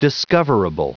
Prononciation du mot discoverable en anglais (fichier audio)
Prononciation du mot : discoverable